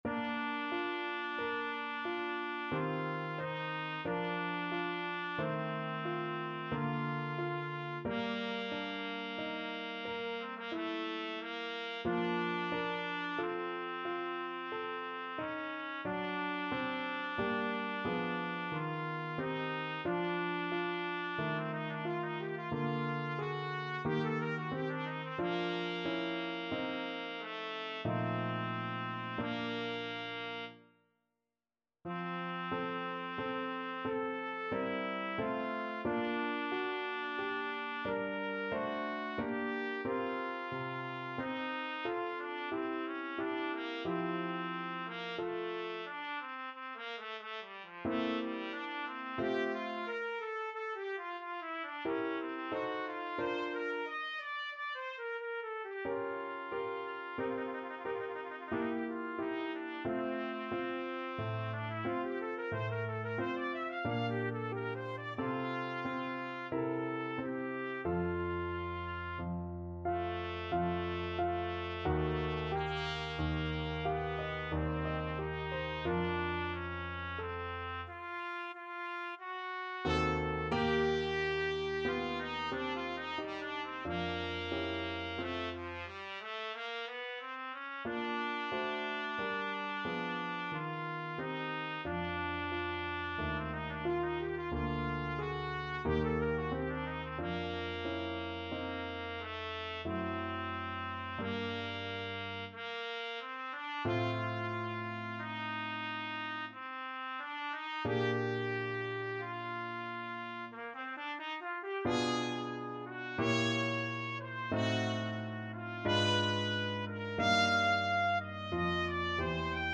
Trumpet
Bb major (Sounding Pitch) C major (Trumpet in Bb) (View more Bb major Music for Trumpet )
Adagio =45
F4-G6
3/4 (View more 3/4 Music)
Classical (View more Classical Trumpet Music)